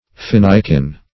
Search Result for " finikin" : The Collaborative International Dictionary of English v.0.48: Finikin \Fin"i*kin\, a. [Fine, a. + -kin.]